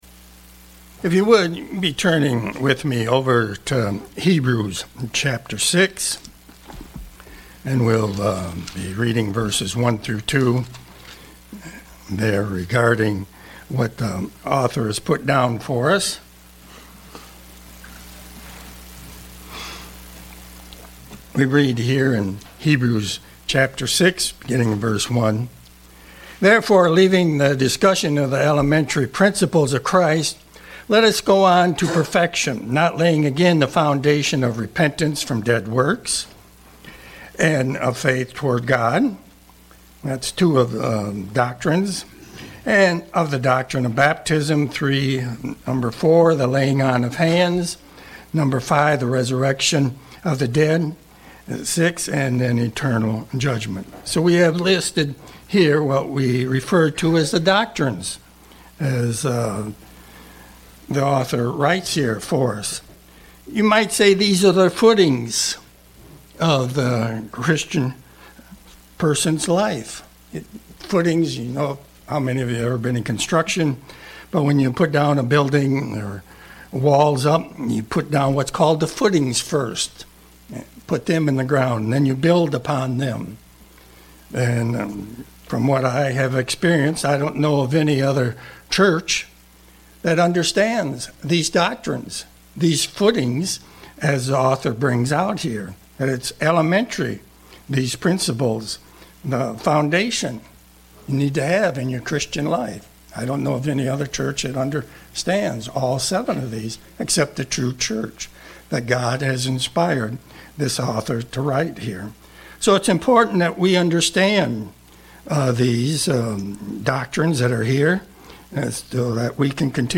Sermons
Given in Ann Arbor, MI